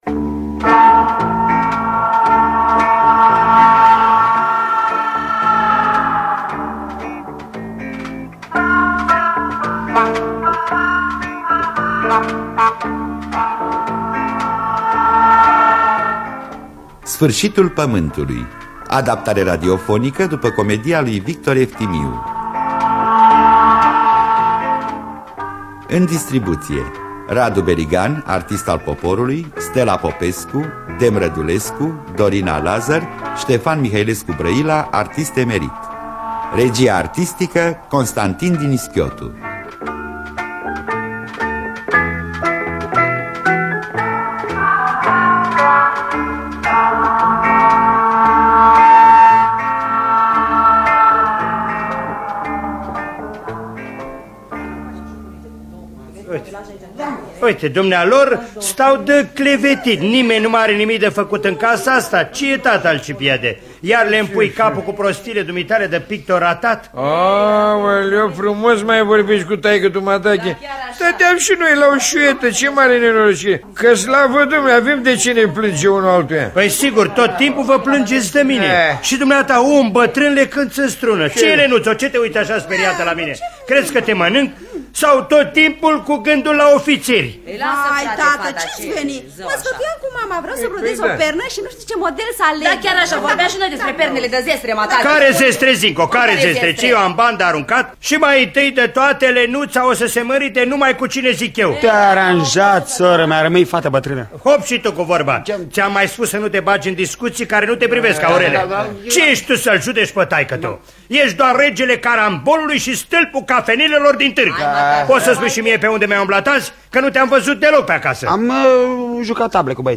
Victor Eftimiu – Sfarsitul Pamantului (1981) – Teatru Radiofonic Online